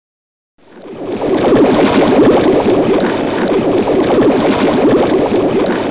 longbubs.mp3